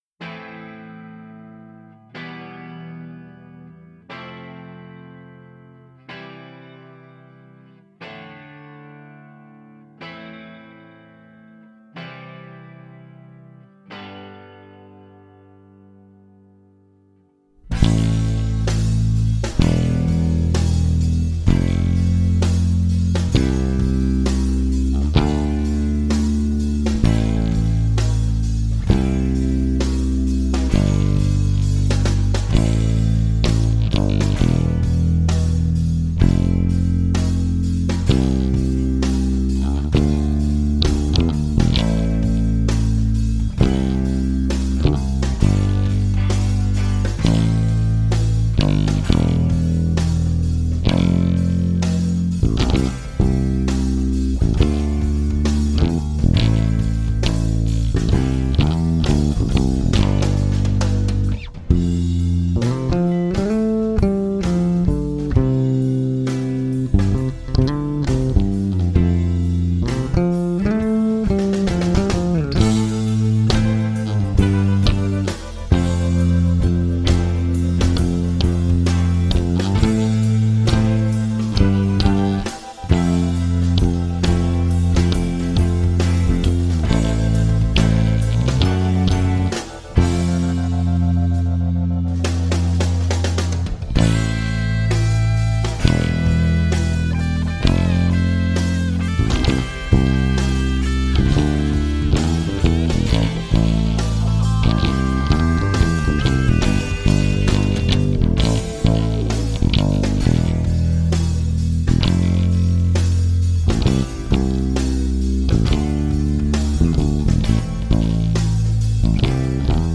instrumental
Bass only